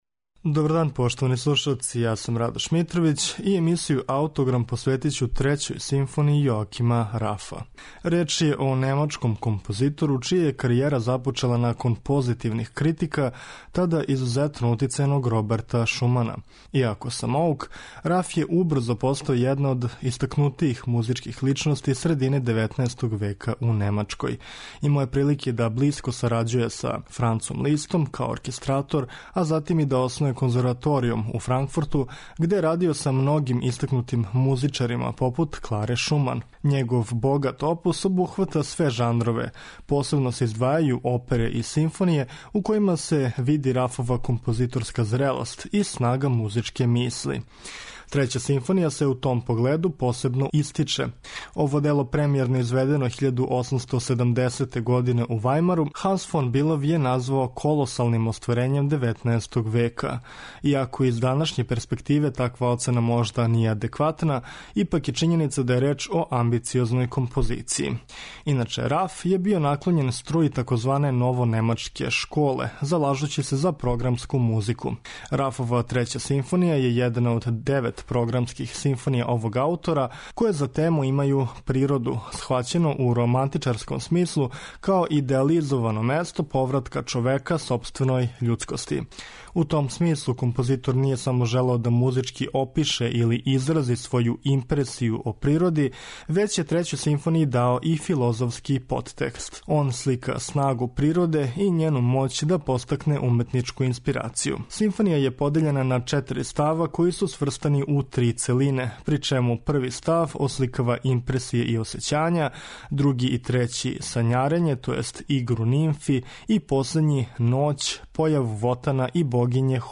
Трећа симфонија јесте једна од укупно 9 симфонија са програмом која музички осликава природу, схваћену у романтичарском смислу, као идеализовано место које омогућава повратак човека својој људскости.